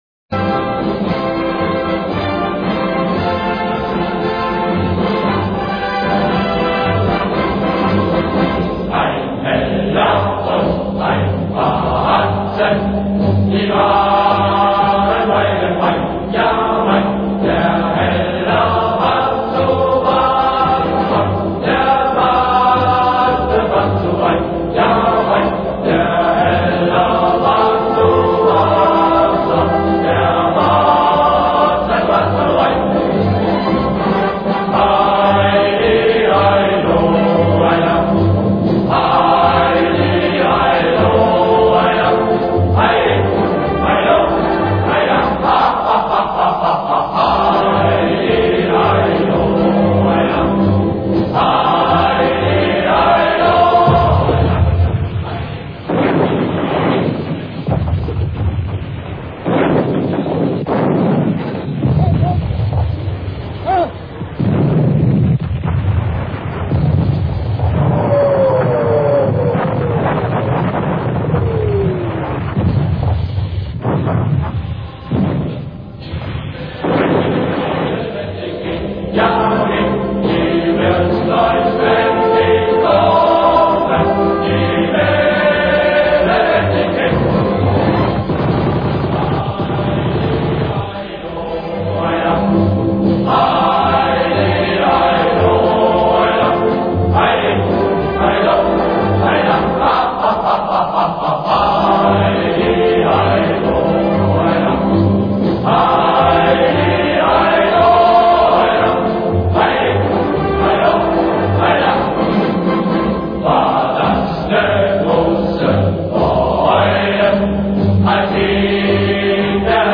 Вот как она тогда звучала (фонограмма из кинообозрения «Degeto Weltspiegel», номер 25, 1941 год):